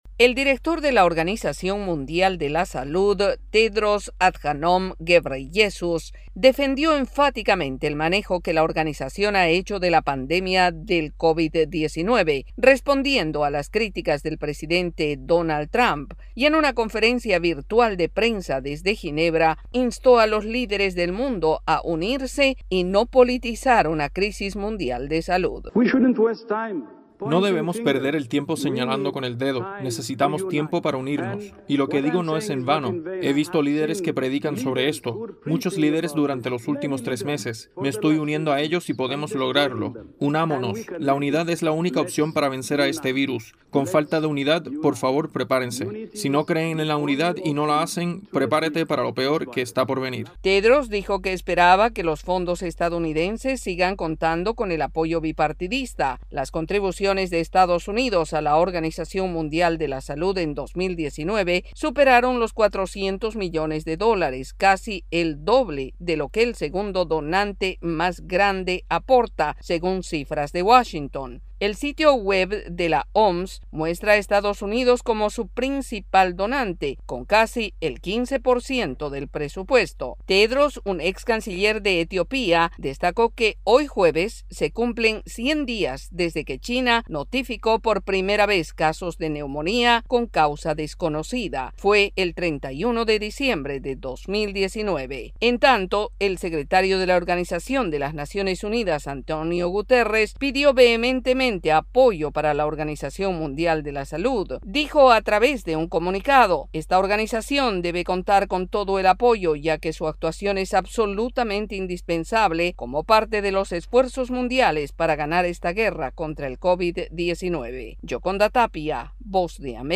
La Organización Mundial de la Salud pidió unidad de los líderes mundiales para vencer la pandemia del COVID 19 y su director general Tedros Adhanom Ghebreyesus enfatizó en que es momento de poner la política en cuarentena. El informe